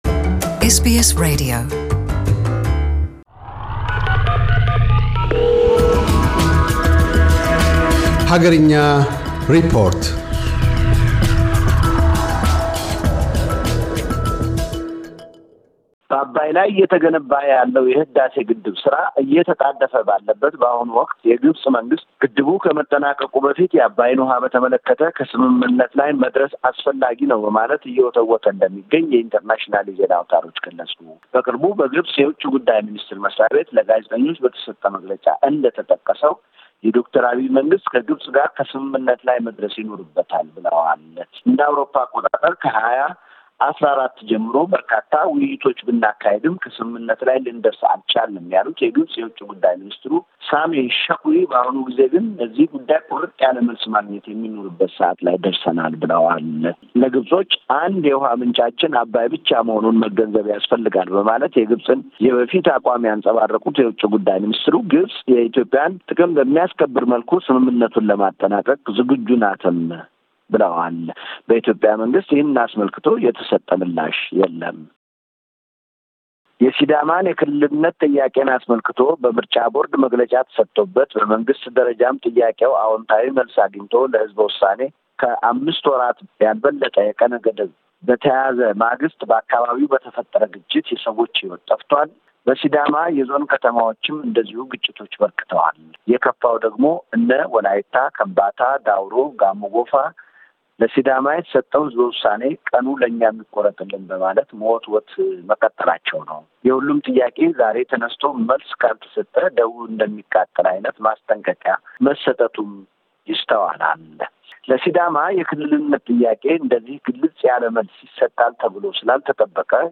አገርኛ ሪፖርት - የግብጽ ውጭ ጉዳይ ሚኒስትር አገራቸው የሕዳሴ ግድብ ከመጠናቀቁ በፊት ከኢትዮጵያ ጋር ከስምምነት ላይ መደረስ እንዳለበት መግለጣቸውን ቀዳሚ ትኩረቱ አድርጓል።